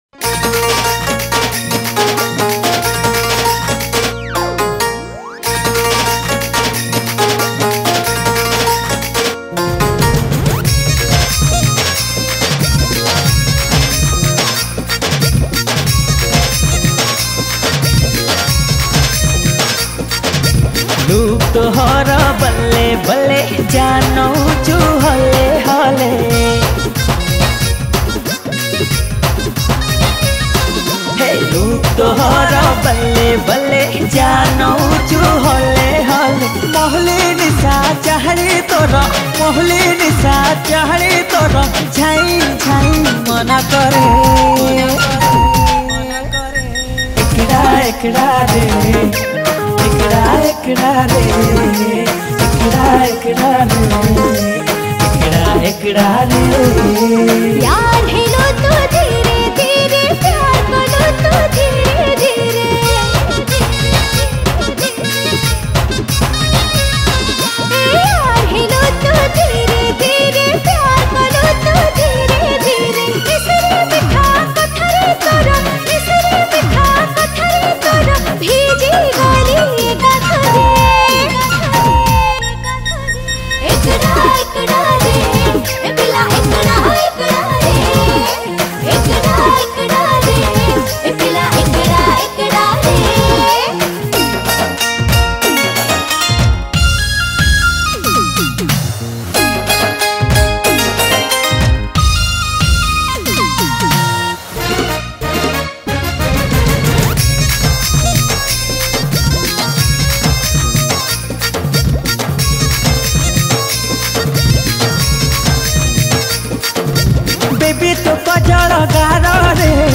Studio Version
Keybord